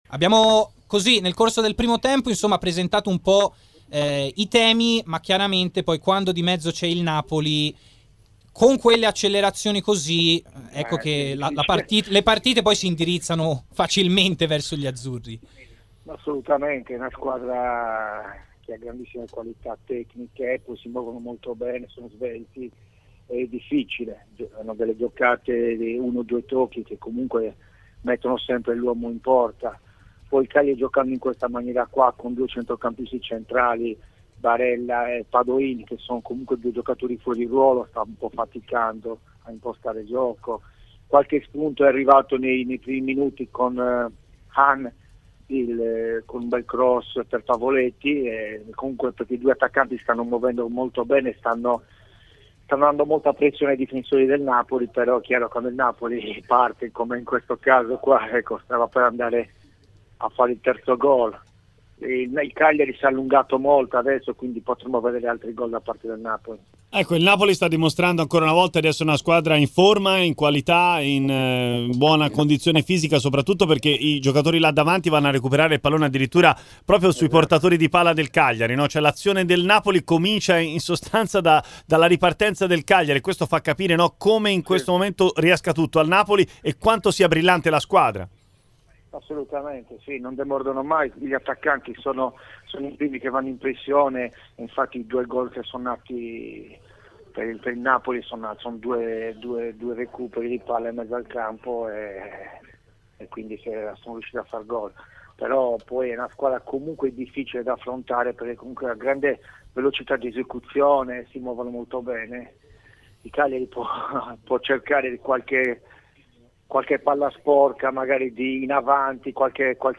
Gianluca Festa, ex giocatore ed allenatore del Cagliari, a commento del secondo tempo di Cagliari-Napoli.